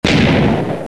Play minecraft boom sfx soundboard button | Soundboardly
minecraft-boom-sfx.mp3